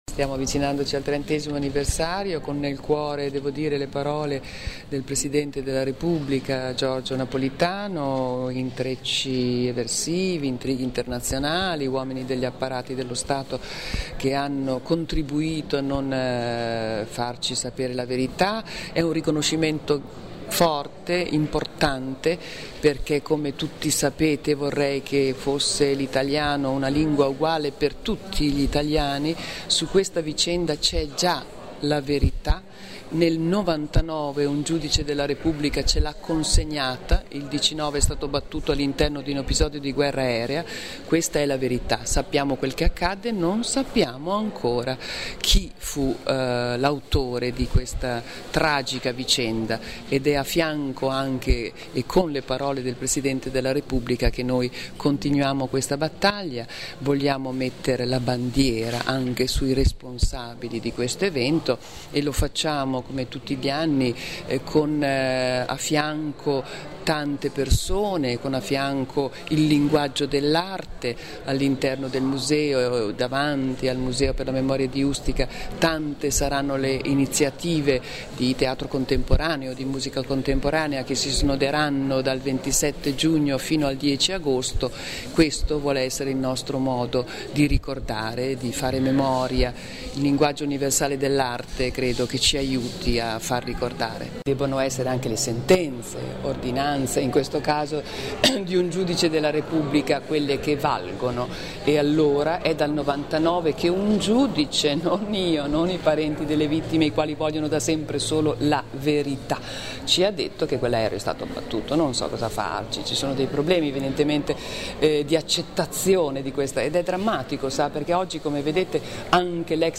Nel corso della conferenza stampa, alla presenza del commissario Anna Maria Cancellieri, Daria Bonfietti ha ricordato tutti gli appuntamenti dedicando un ricordo particolare  all’attore Corso Salani recentemente scomparso. “Siamo assediati dalla verità” ha affermato Bonfietti riferendosi all’ultimo tassello che ormai manca per dare una verità storica alla strage, e cioè la nazionalità del caccia che lanciò il missile e il motivo che portò all’abbattimento del DC9.
Ascolta la presidente dell’Associazione dei parenti delle vittime Daria Bonfietti